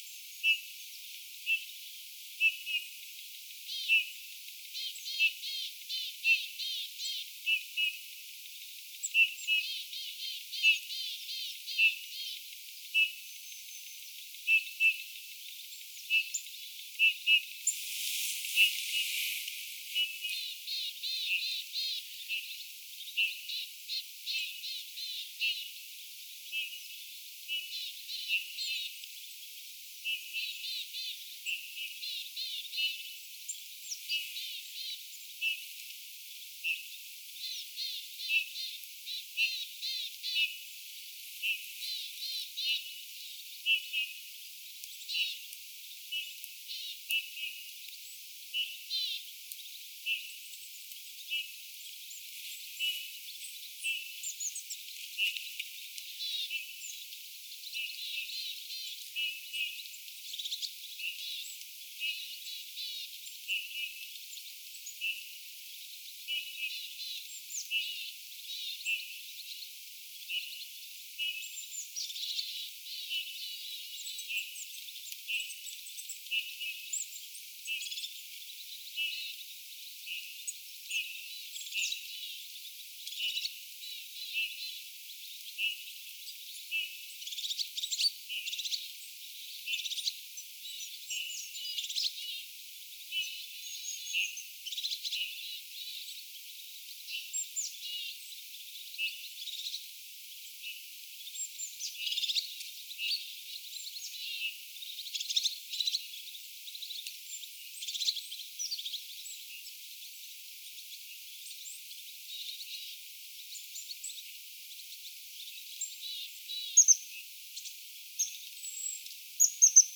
kuvien punatulkkukoiras ääntelee
Nyt on tullut tuollaisia punatulkkuja,
joiden hjy-äänet ovat tuolla tavoin väriseviä.
tuollaista_punatulkkukoiraan_aantelya.mp3